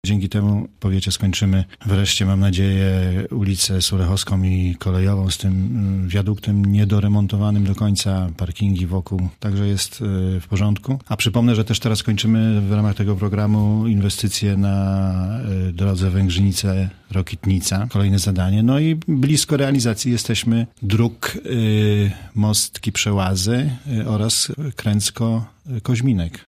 Zbigniew Szumski, starosta świebodziński, który był porannym gościem Radia Zachód mówi, że za te środki uda się zrealizować ważne inwestycje dla lokalnej społeczności.
Cała rozmowa ze Zbigniewem Szumskim do wysłuchania na stronie Polskiego Radia Zachód.